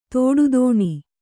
♪ tōḍu dōṇi